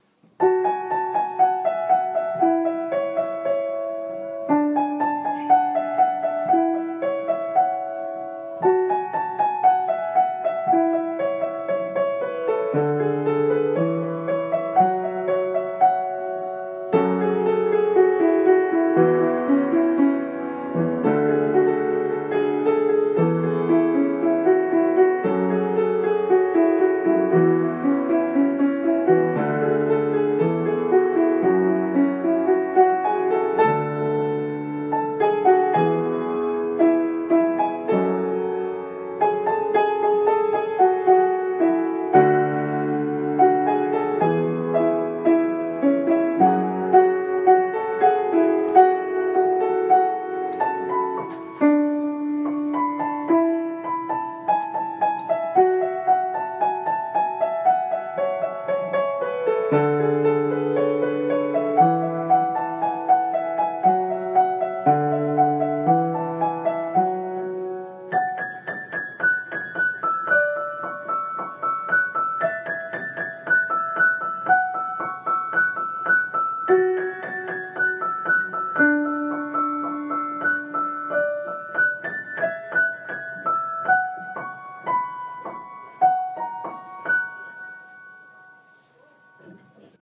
声劇＊シンデレラ＊台本